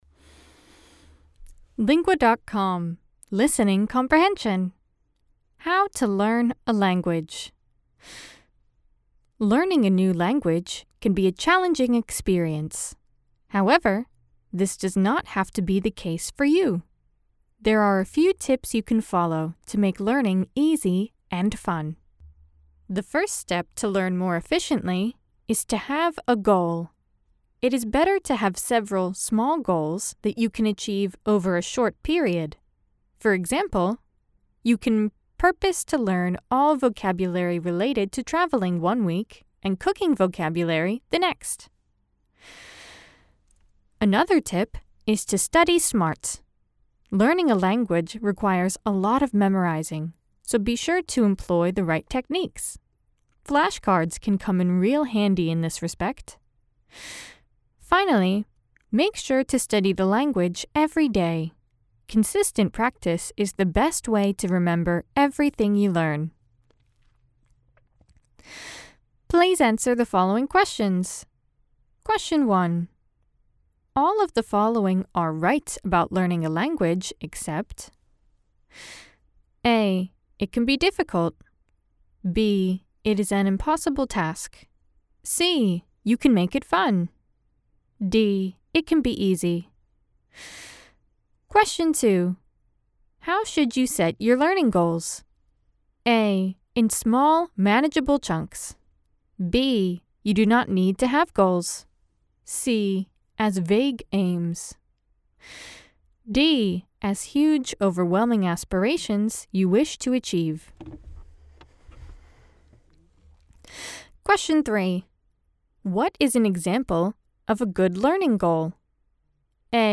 Estados Unidos